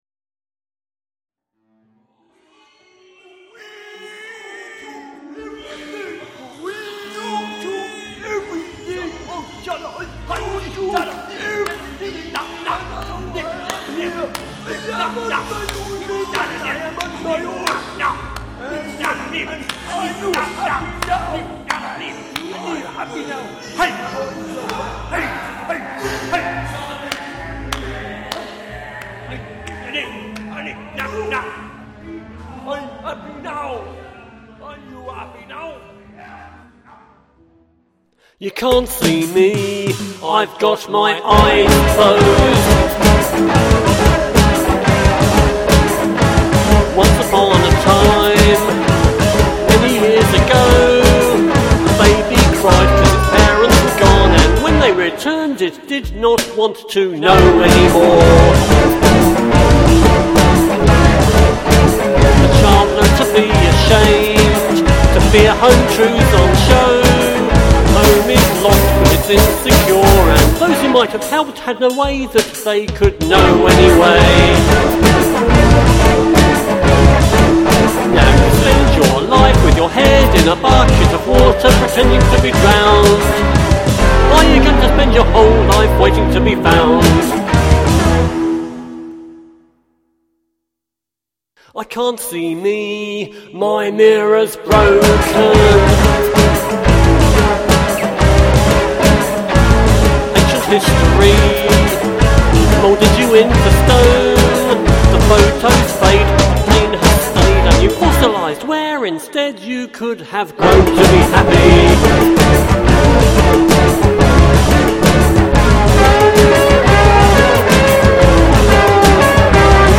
Kinda cheerful.”